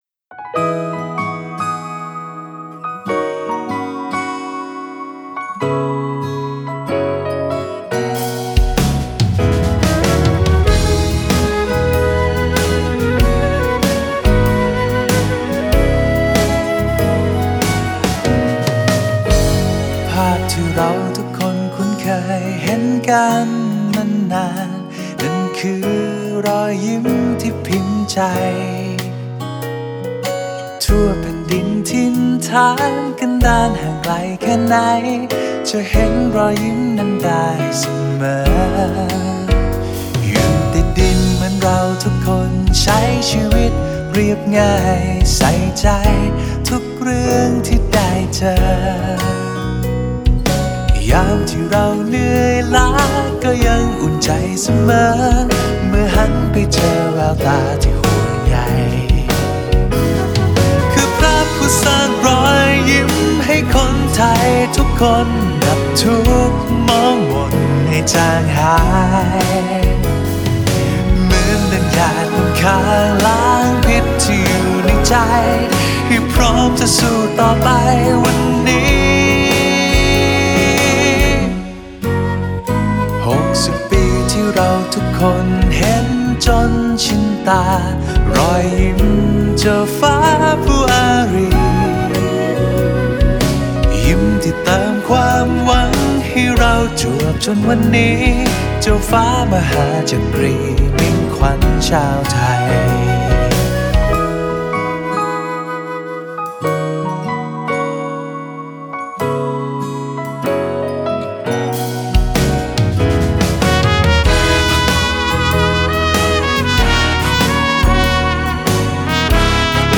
เสียงเพลง